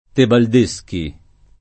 [ tebald %S ki ]